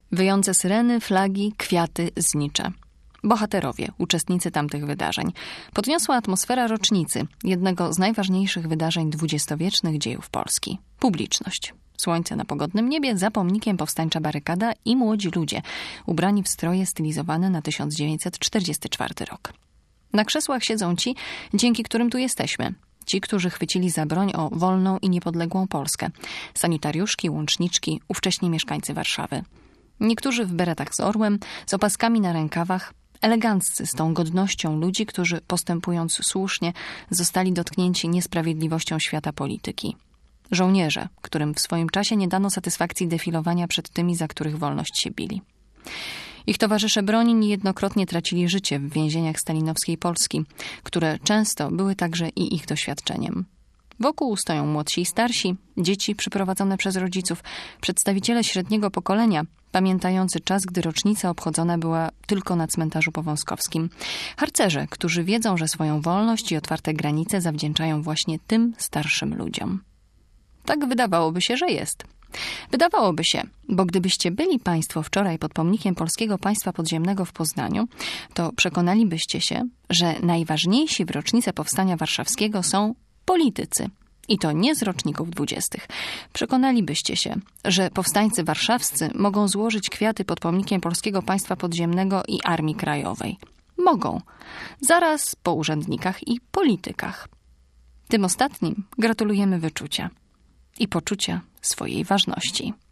Obserwowała ją nasza reporterka i ona właśnie podzieliła się swoimi wrażeniami.